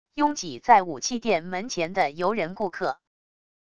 拥挤在武器店门前的游人顾客wav音频